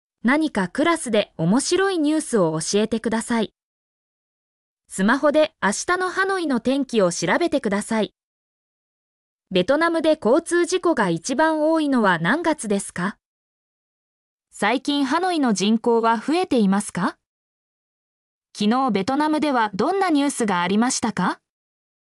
mp3-output-ttsfreedotcom-7_NfHx8KPa.mp3